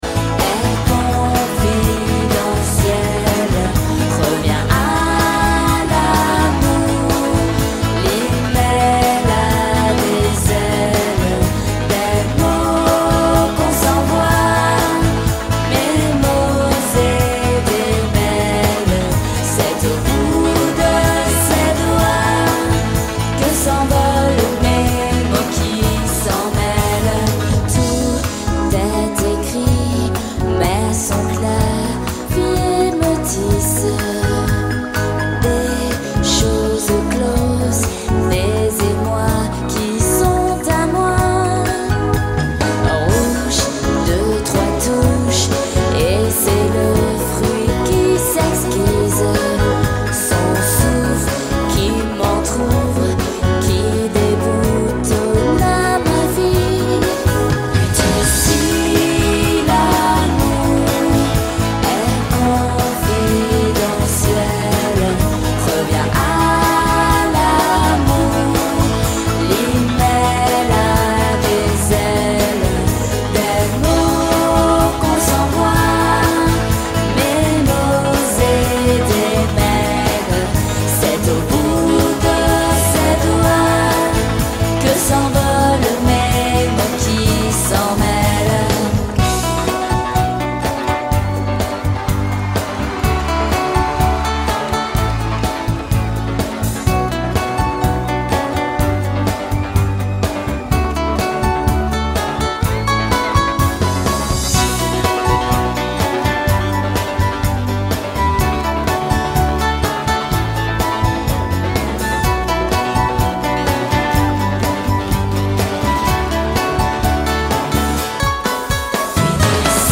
Live HD